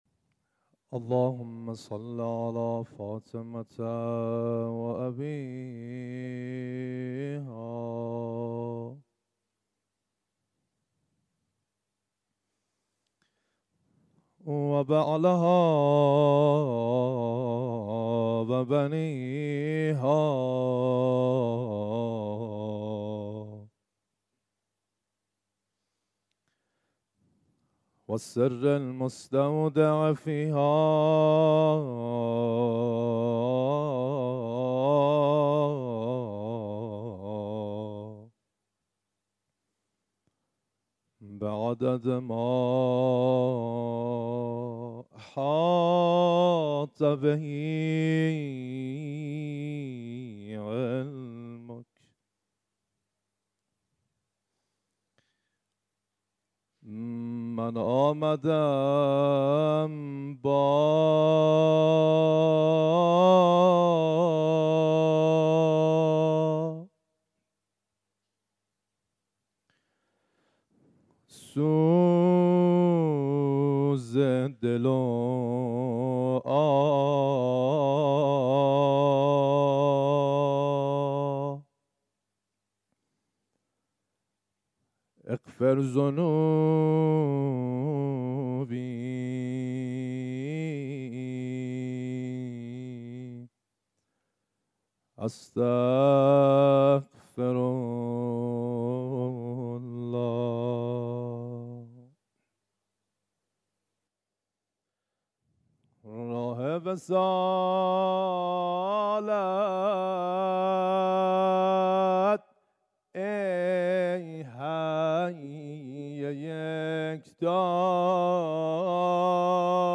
رشته دعاخوانی